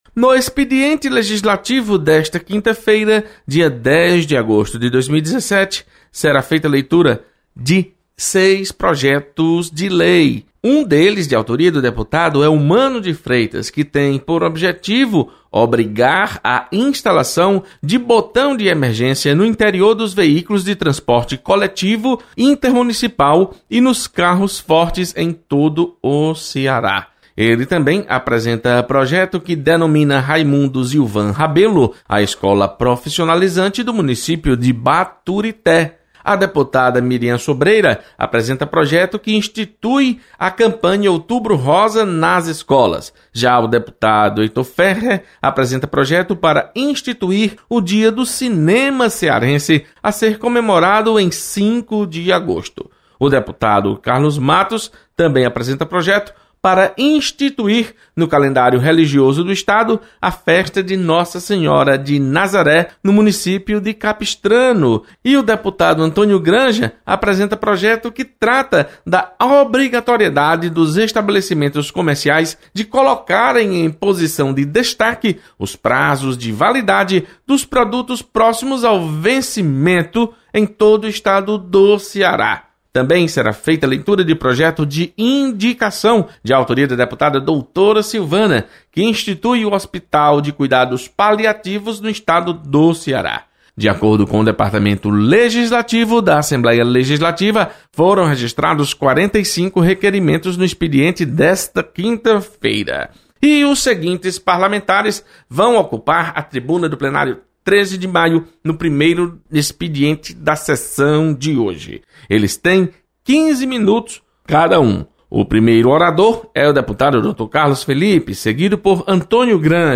Acompanhe as informações do expediente legislativo desta quinta-feira. Repórter